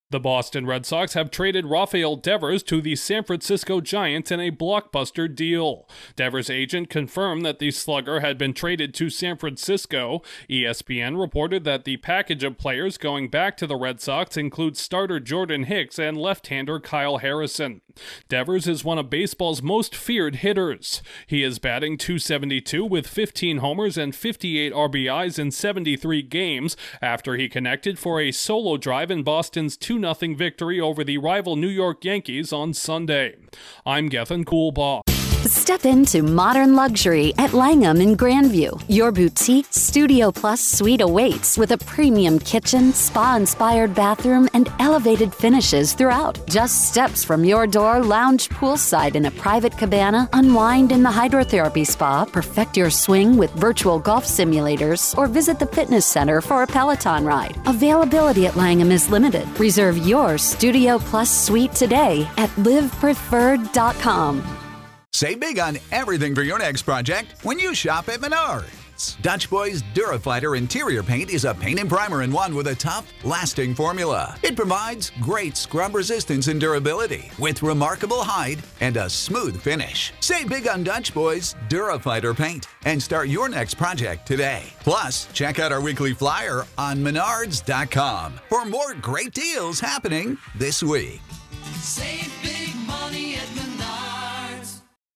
A three-time All-Star and former World Series champion is on the move in a surprising trade. Correspondent